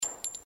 bulletshells01.mp3